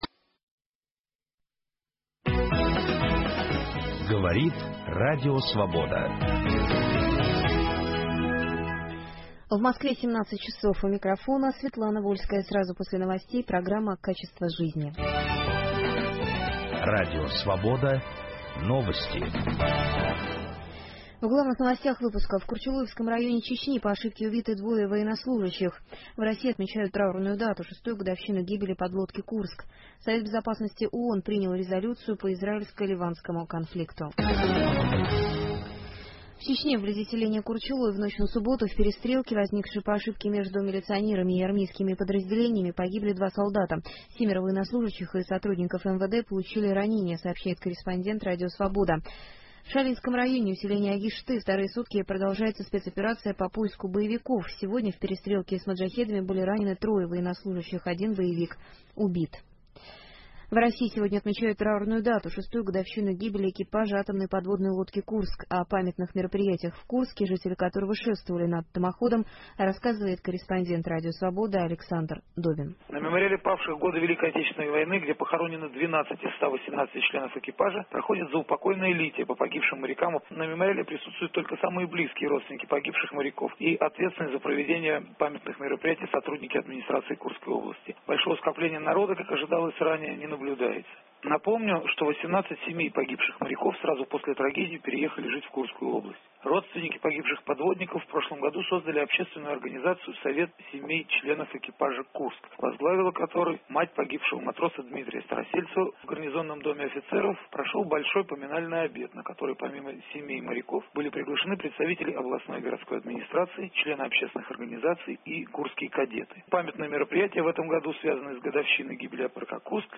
будет отвечать ведущий российский футбольный судья, арбитр ФИФА Валентин Иванов